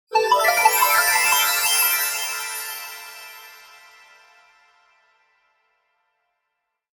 Magical Sparkling Transition Sound Effect
Description: Magical sparkling transition sound effect. Add a touch of magic to your projects with these sparkling fairy dust transition chimes. Perfect for videos, games, animations, and fantasy scenes that need an enchanting, whimsical audio flair.
Genres: Sound Logo
Magical-sparkling-transition-sound-effect.mp3